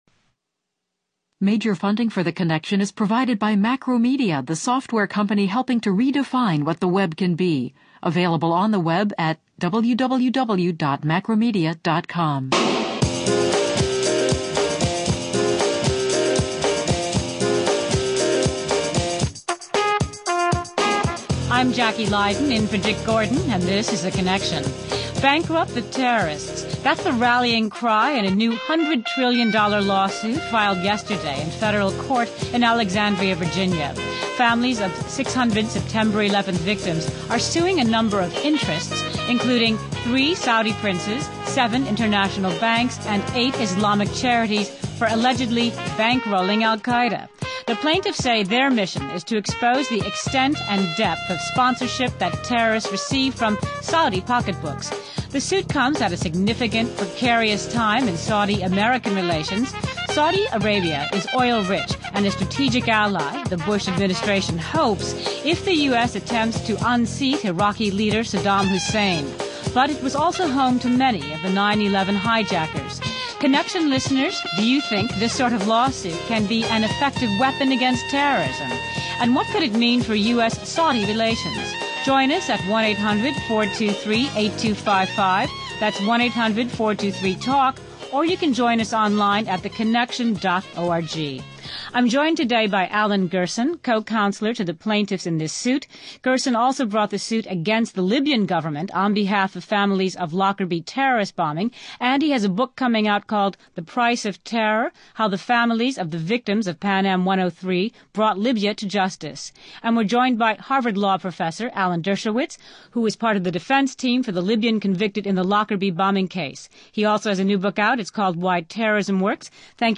How the Families of the Victims of Pan Am 103 Brought Libya to Justice” Alan Dershowitz, law professor at Harvard University and author of the new book “Why Terrorism Works.”